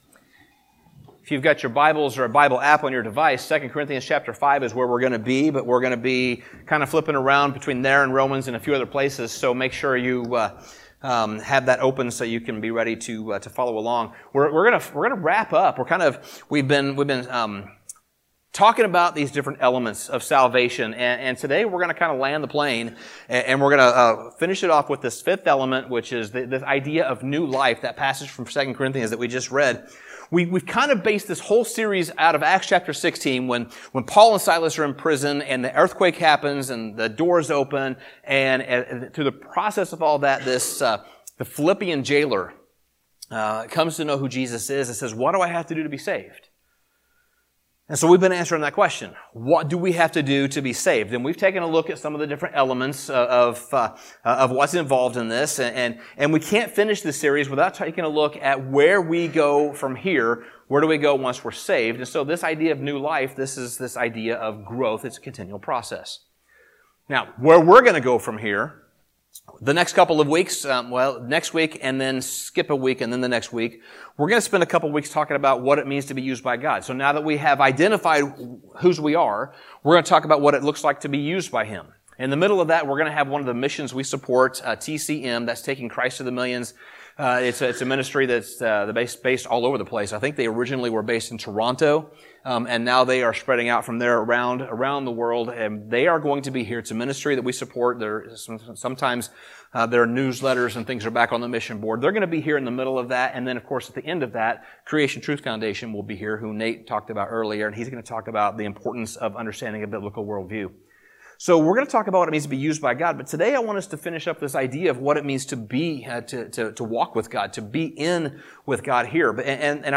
Sermon Summary The fifth and final element in our series is as much an outcome of our salvation as it is a part of the process of our salvation.